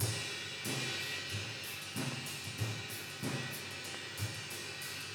RIDE_LOOP_8.wav